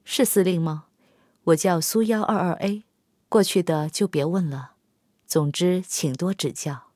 SU-122A登场语音.OGG